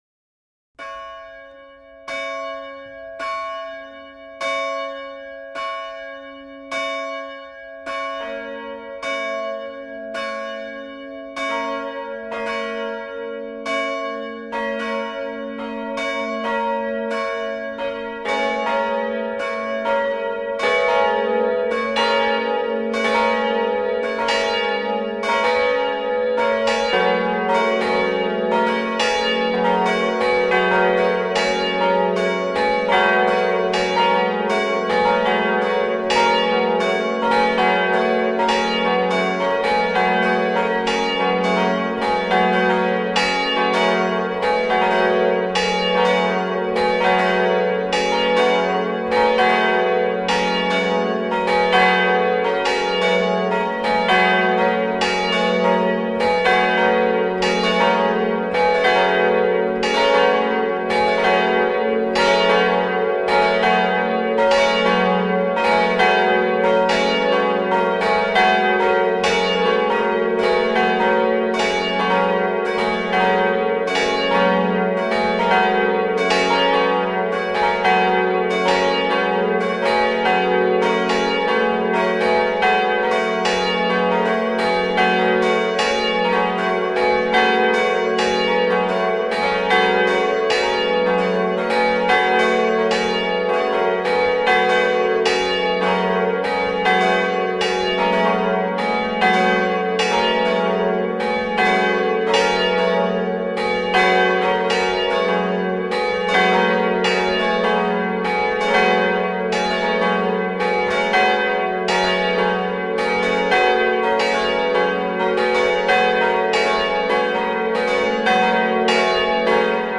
Il s'agit d'un petit reportage campanaire sur l'église de La Hulpe.
Au sommet de la tour, au niveau des abat-son et sous la charpente se cachent quatre cloches.
Les cloches sont commandées par un boîtier automatique Campa.
Vous pouvez écouter ci-dessous la volée pour la messe (6:10mn) :